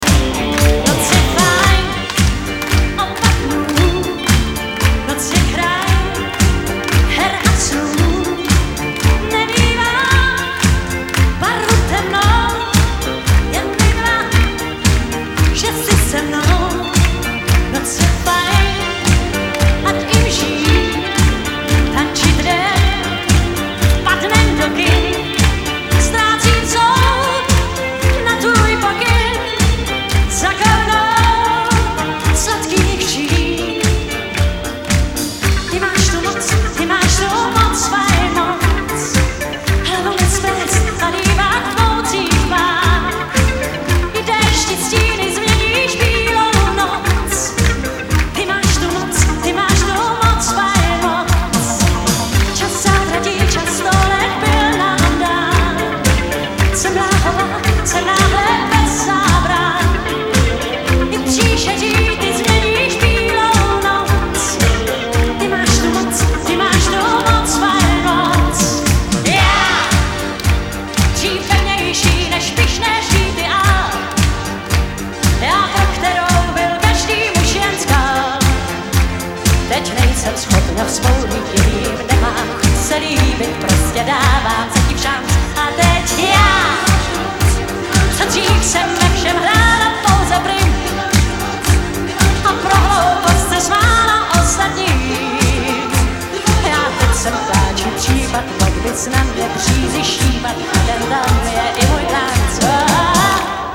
POP, ROCK, SOUL A DISCO